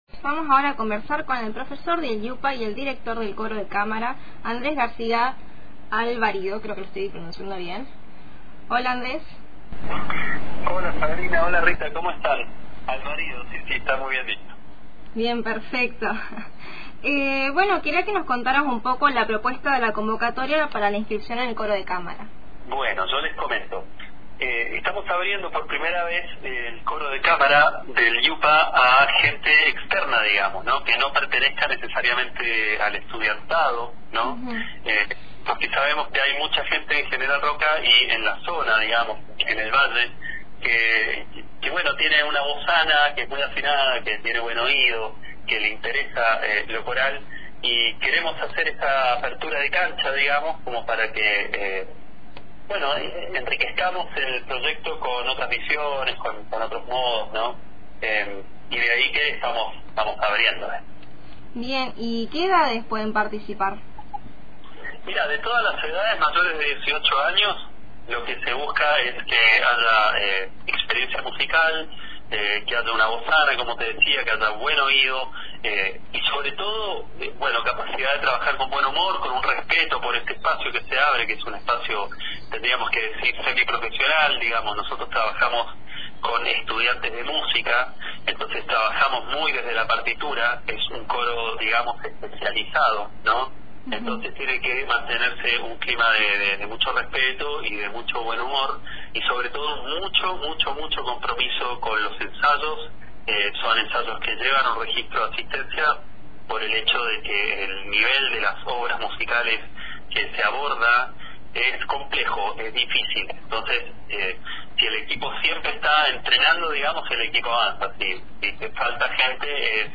Para enterarte más te dejamos la entrevista completa